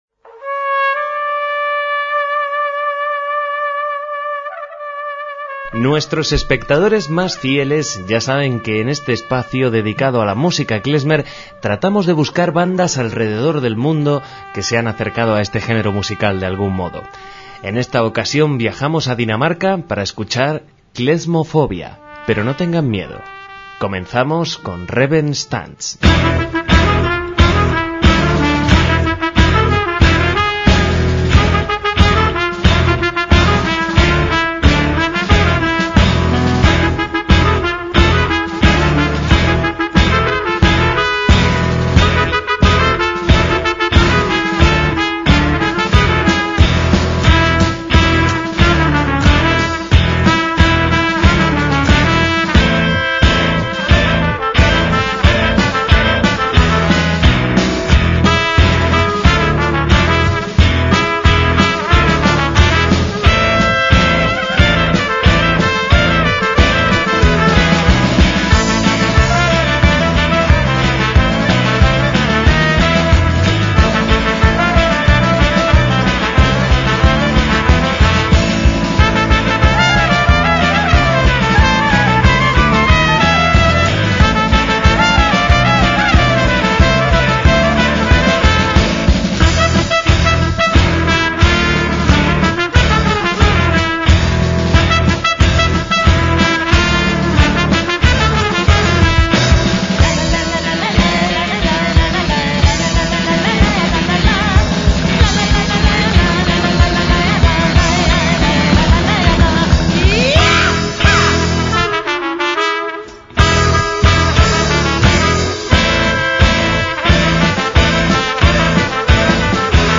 MÚSICA KLEZMER
banda danesa de música klezmer
clarinetes
trompeta
guitarras
balalaika contrabajo
batería
voz